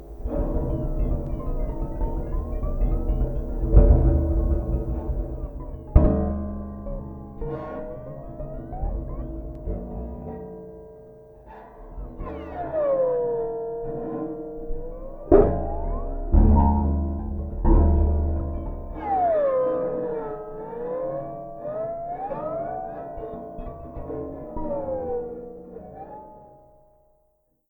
Les différentes techniques de jeu une fois enregistrées sont placés dans un échantillonneur de façon à faciliter le squelette de la composition, en voici quelques exemples :
slides.mp3